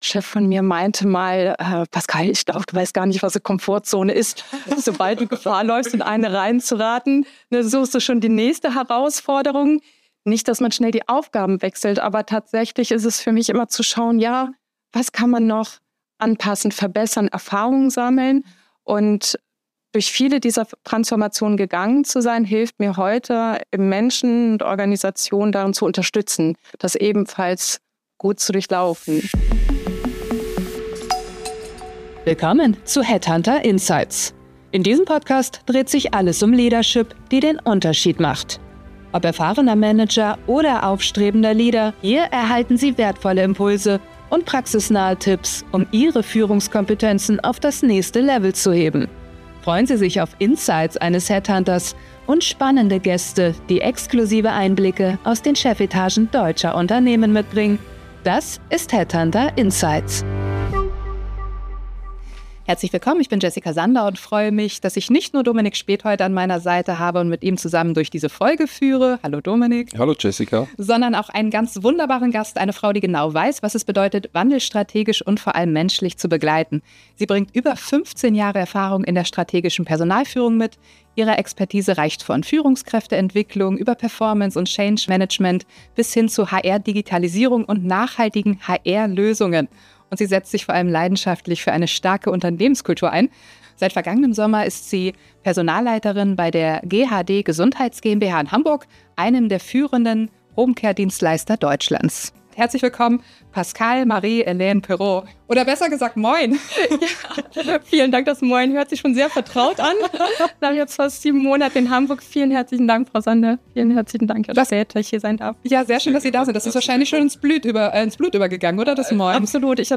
#14 Ehrlich. Menschlich. Mutig - so geht Transformation. Im Gespräch